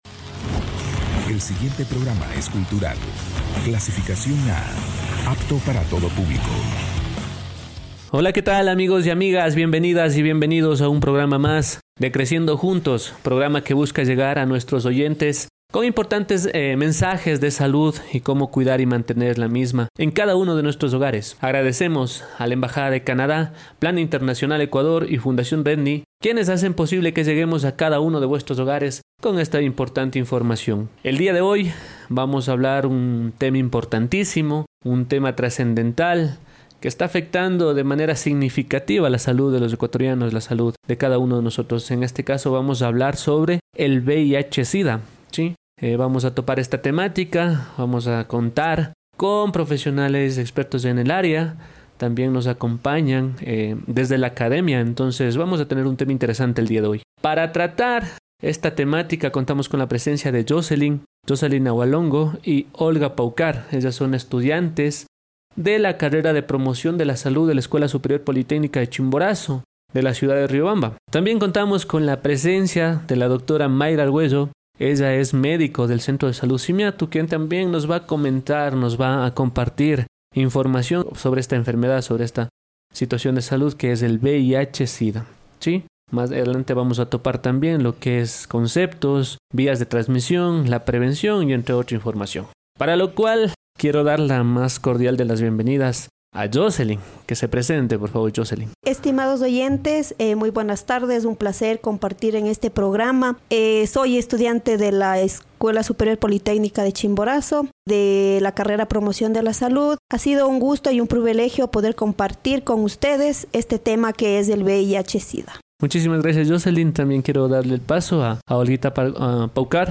Programa radial Creciendo juntos Ep. 12 – Creciendo juntos
En este episodio, las doctoras ofrecen una explicación clara sobre el VIH, su historia y su impacto en la salud global.
La entrevista enfatiza que, con el tratamiento adecuado, las personas con VIH pueden llevar vidas saludables y plenas, resaltando la importancia de la detección temprana y el acceso a servicios de salud.